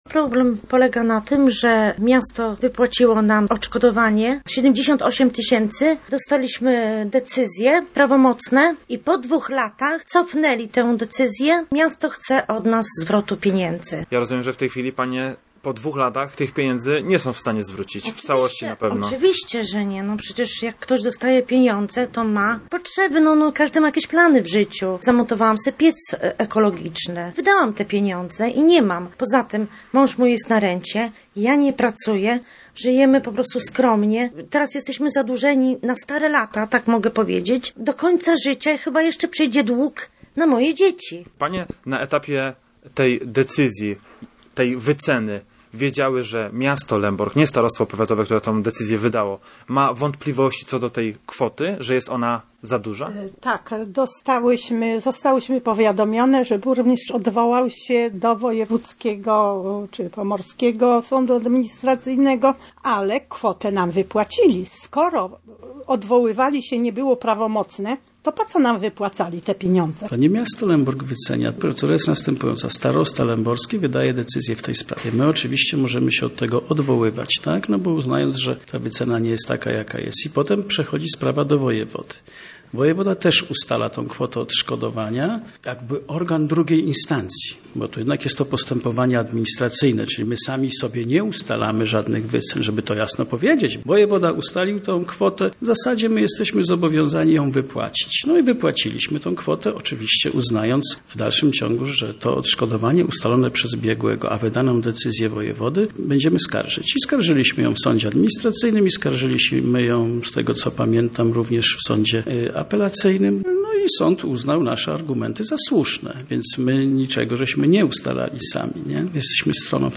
Więcej w materiale naszego dziennikarza: